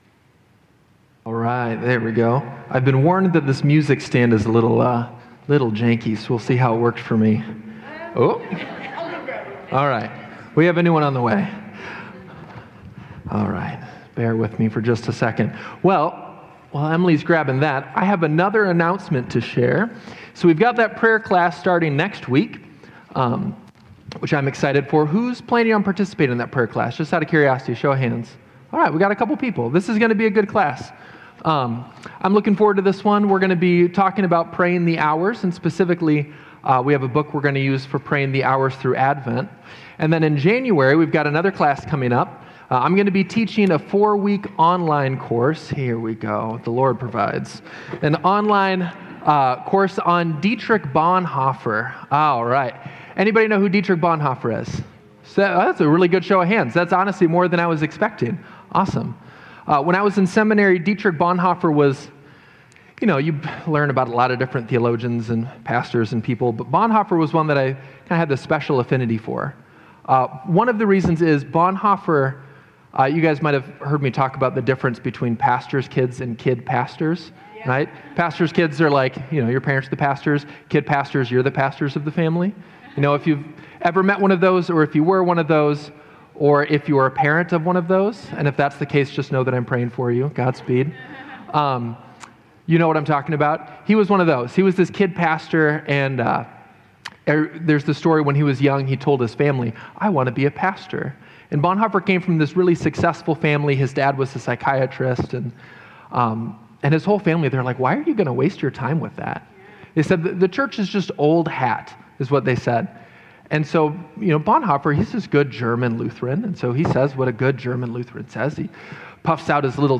09 Nov 2025 | Sermon On the Mount: Judge Not